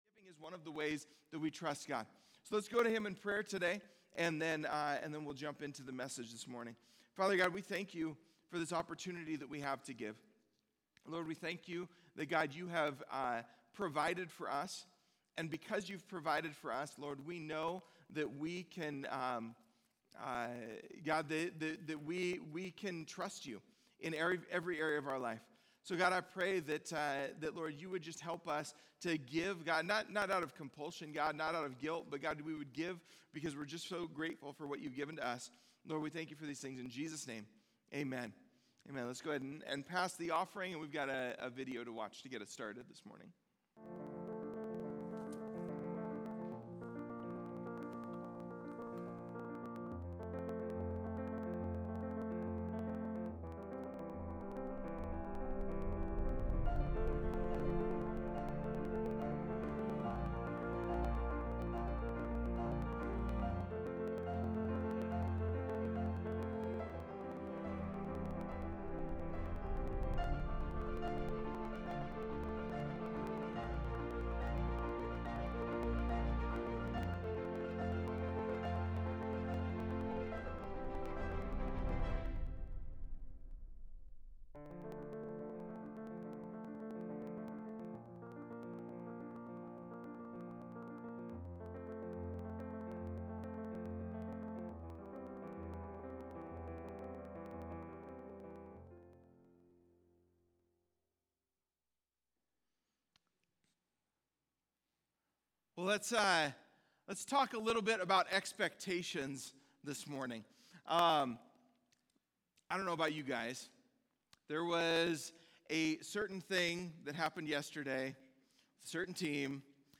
1-18-Sermon-MP3.mp3